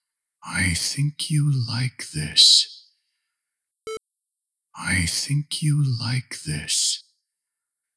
How to remove phonograph-like crackle and hiss noises appearing after loud "S" and "F" vocals in audio?
Hello! I’m getting myself into animation, and I decided to record all speaking scenes first, and then animate them. Now I’m completely stuck with this “sound” stage, and I cannot my current issue for 2 weeks already: when character is pronouncing “S” and “F” sounds loudly, some crackle and hiss always escort these letters.